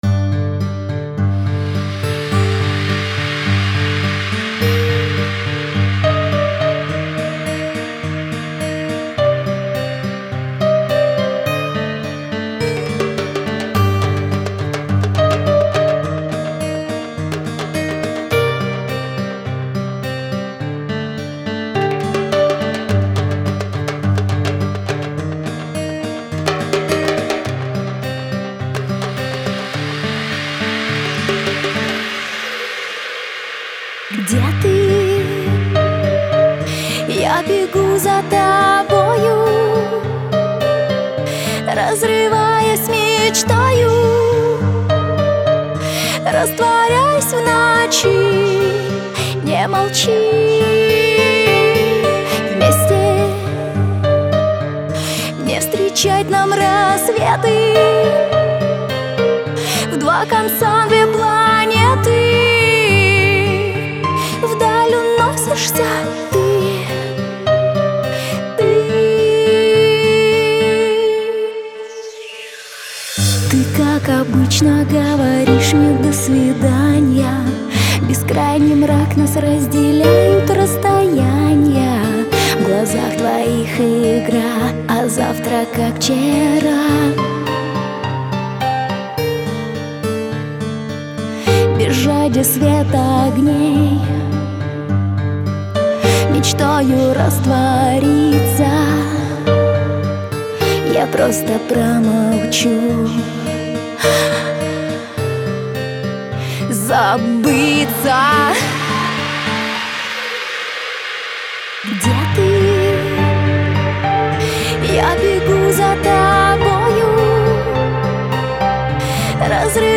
акустическая версия